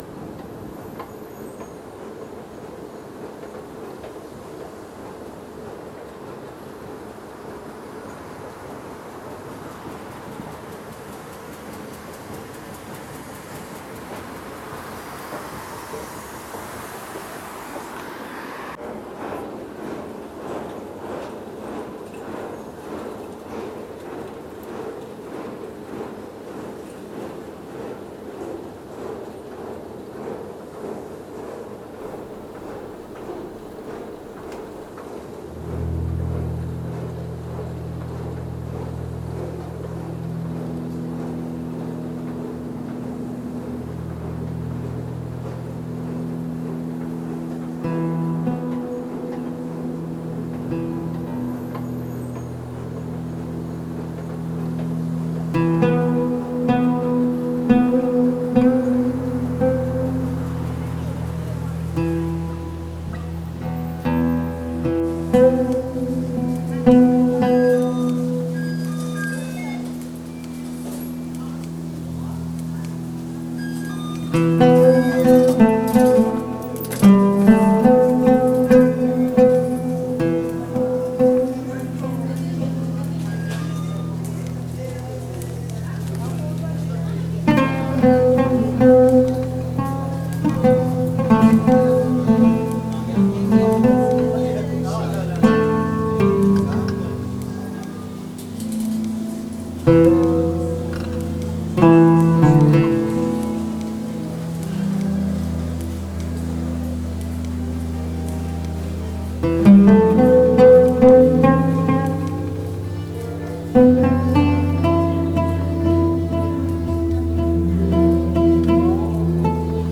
Recorded in Vancouver and Istanbul.
The sounds are quotidian and rather banal.